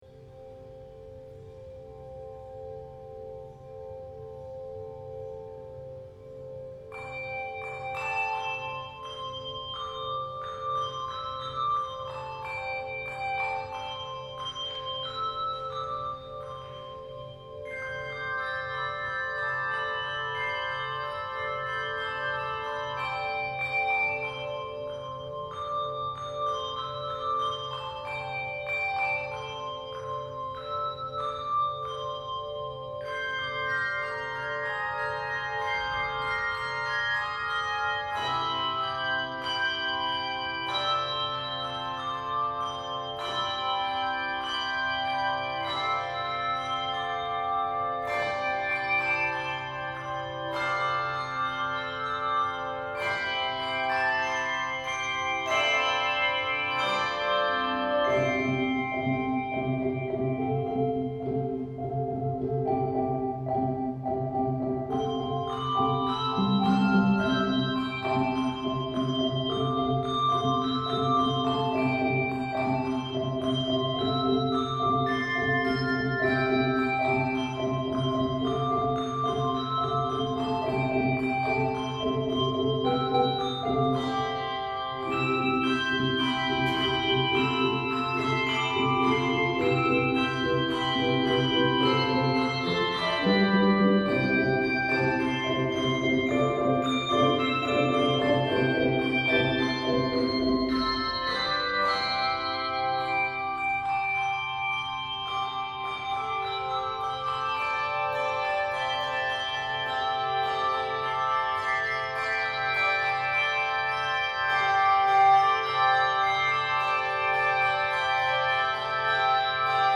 handbells
Keys of C Major and D Major.